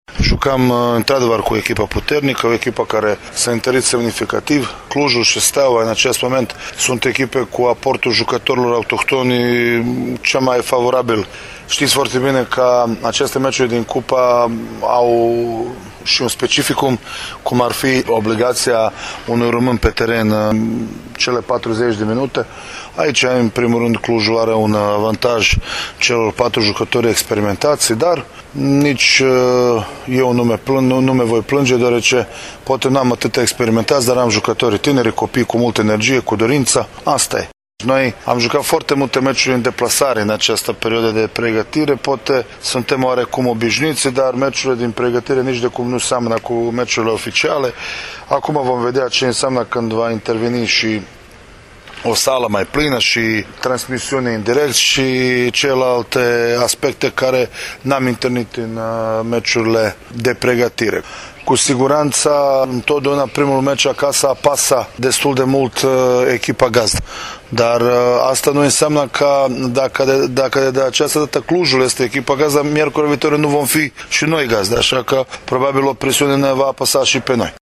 Declaraţia AUDIO